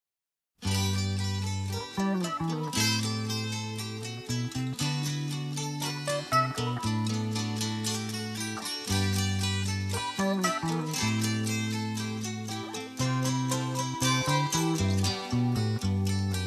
和製ブルースボーカリスト
（MP3, 約258KB）は、ギターとシンセの大変美しい曲です。